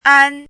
“安”读音
ān
国际音标：Ąn˥